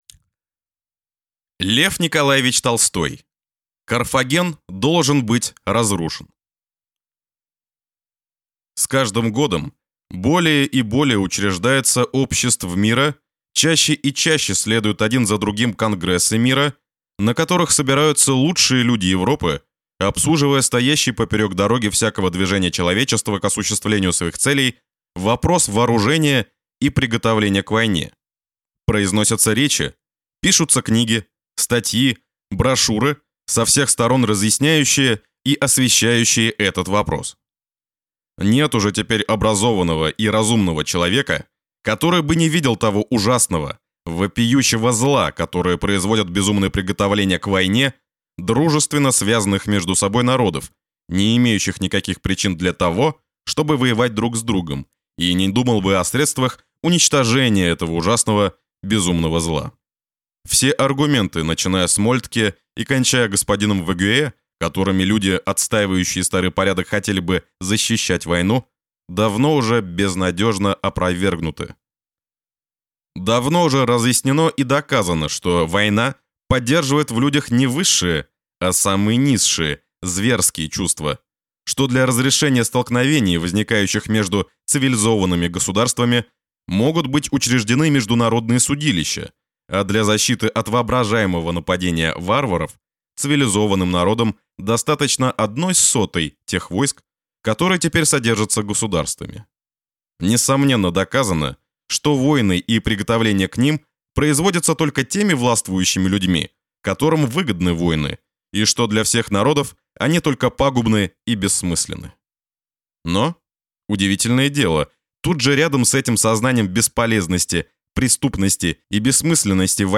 Аудиокнига Carthago delenda est (Карфаген должен быть разрушен) | Библиотека аудиокниг